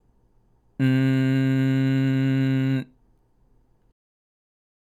①口を「え」の形に開けたまま、鼻からだけ「んー」と声を出します。
これは軟口蓋とベロの根元がくっついたことによって、鼻のトンネルにのみ声が流れている状態です（通称「鼻だけ」）
※見本のグーの鼻からの声